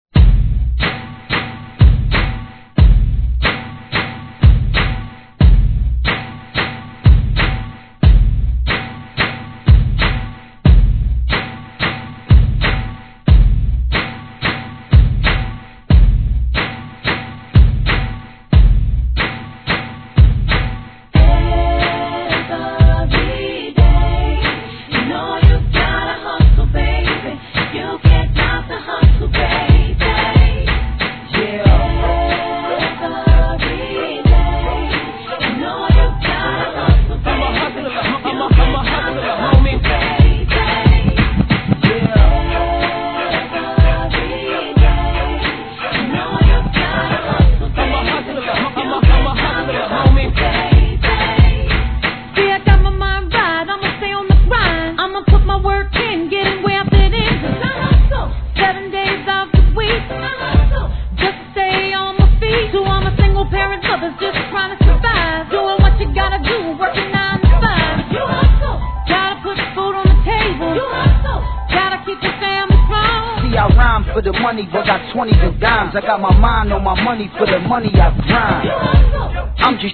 HIP HOP/R&B
人気曲を集め、イントロまで使いやすく長くなってDJには嬉しい限りのコンピVOL.20！